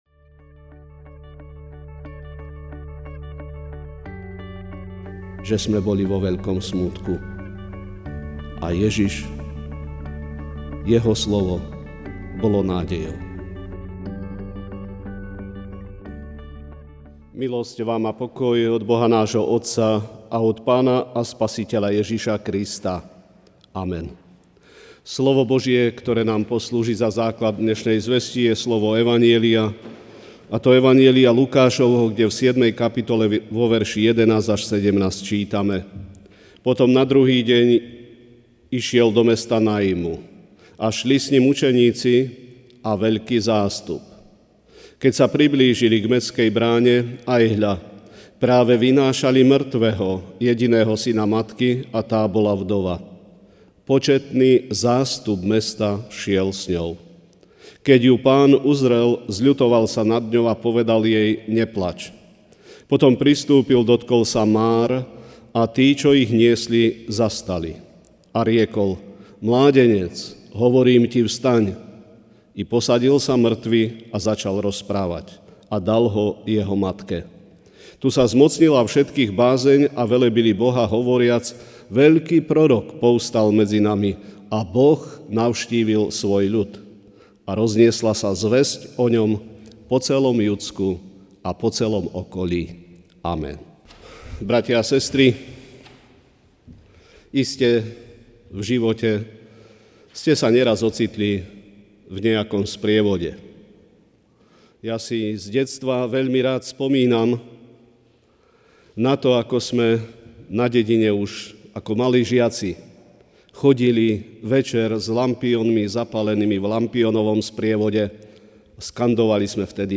Večerná kázeň: Veľká rádosť a nádej! (Lukáš 7,11-17)